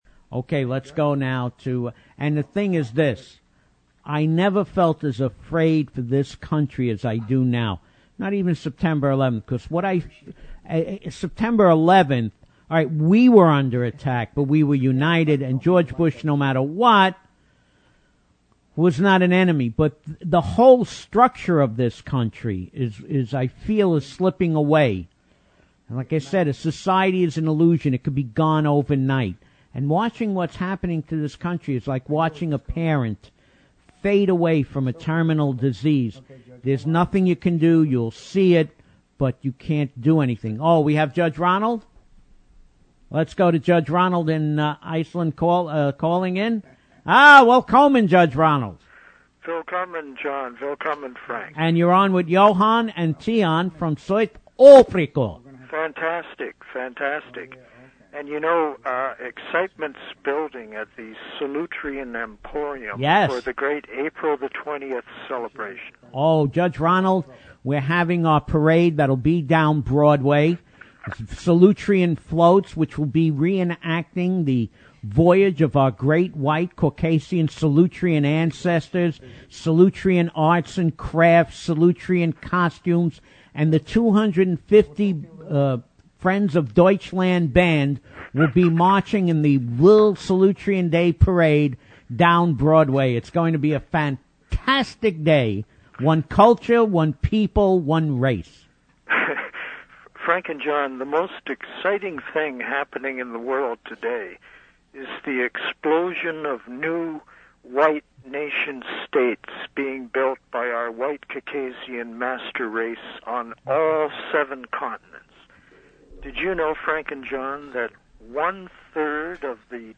Tags: South Africa Propaganda Apartheid The Right Perspective Talk Radio